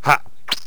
stickfighter_attack1.wav